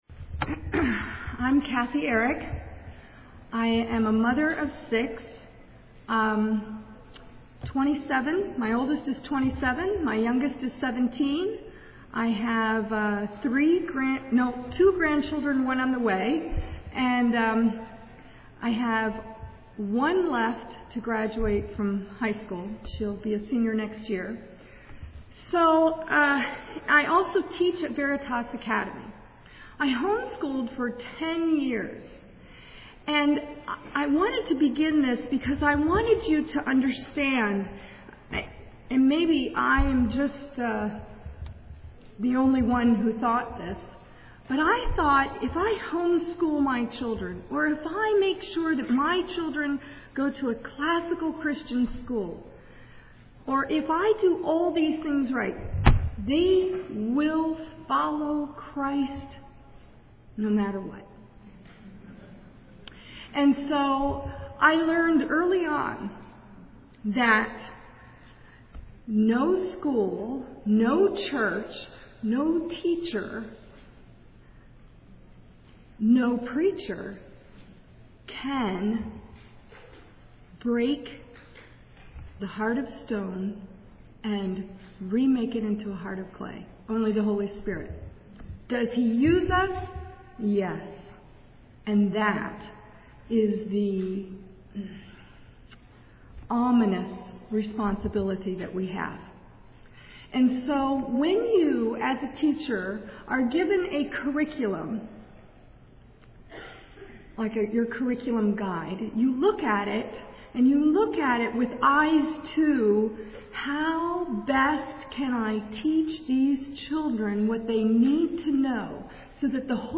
2007 Workshop Talk | 0:50:52 | Curriculum Overviews
The Association of Classical & Christian Schools presents Repairing the Ruins, the ACCS annual conference, copyright ACCS.